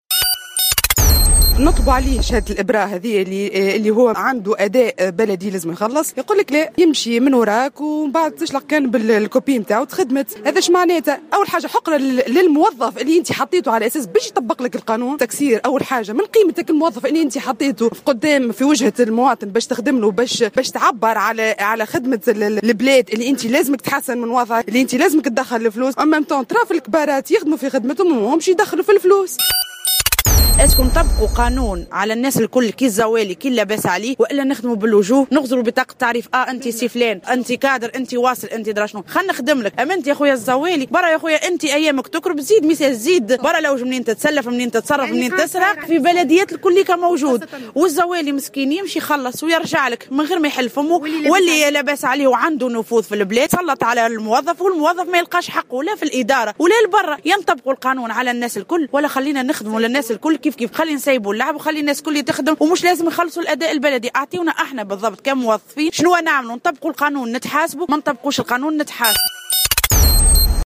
محتجون